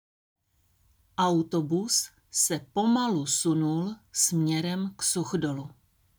Tady si můžete stáhnout audio na výslovnost S – věta.